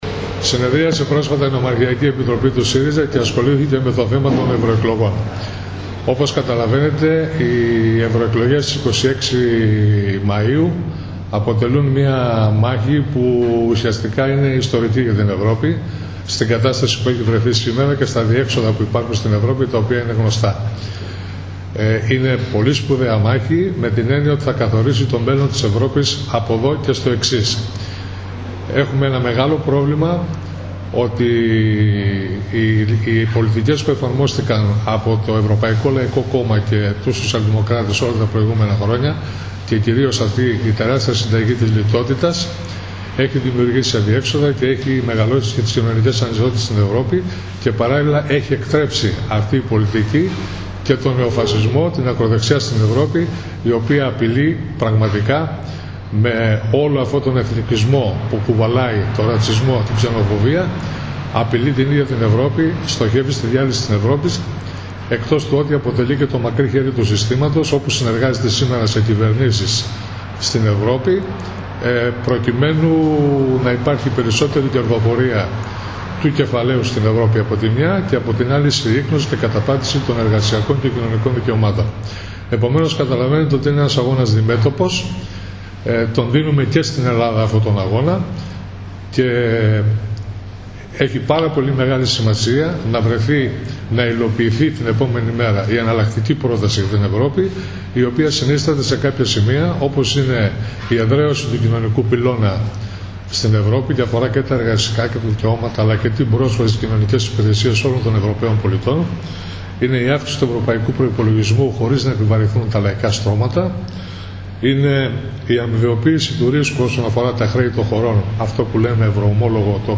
συνέντευξη τύπου